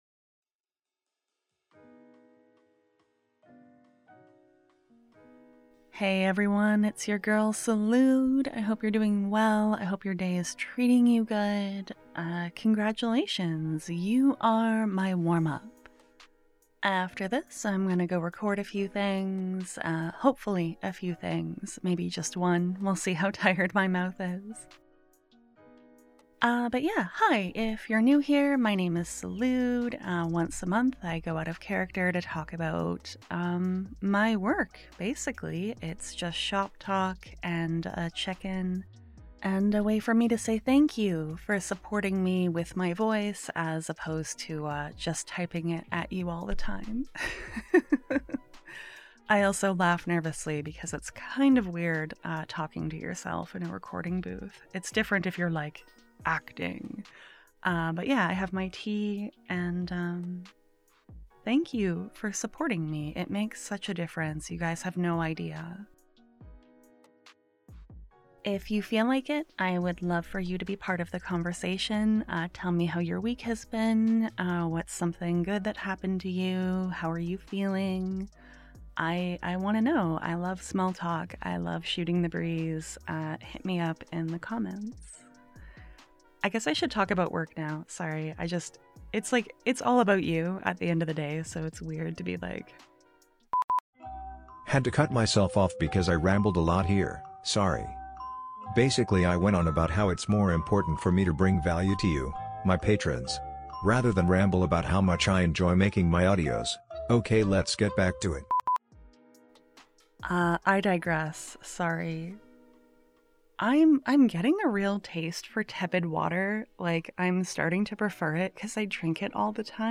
Here's my real self rambling about what I'm working on~XOXO~